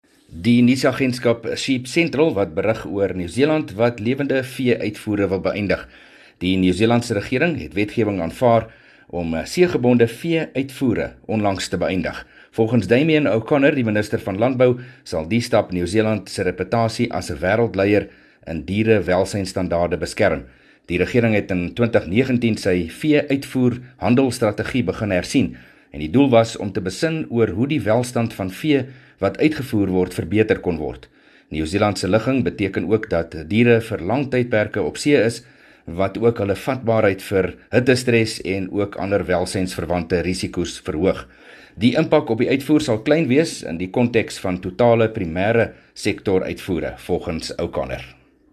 28 Nov PM berig oor lewendehawe-nuus uit Nieu-Seeland